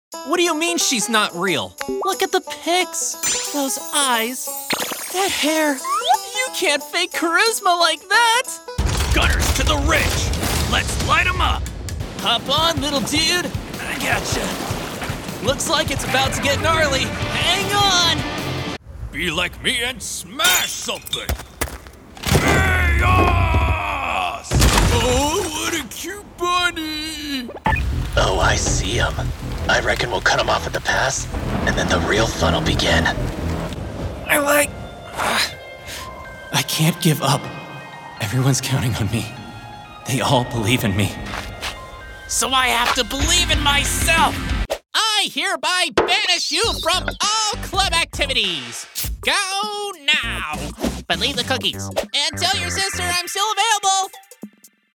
Russian, British, American Southern, General American
Young Adult
Middle Aged
Character Voice
Clients can expect an exceptional performance from a soundproofed home studio with Source-Connect alongside Adobe Audition and Reaper as DAWs of choice.